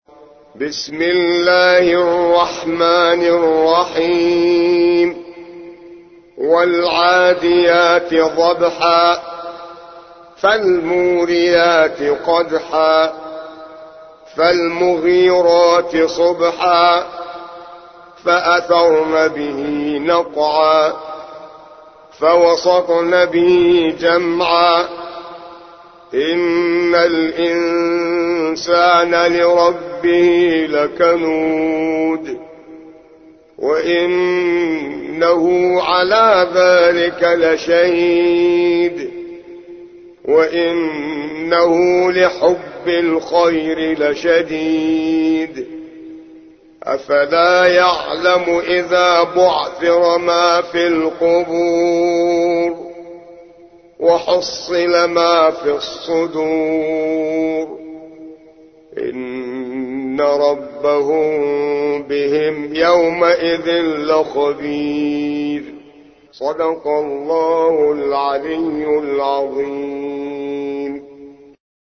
100. سورة العاديات / القارئ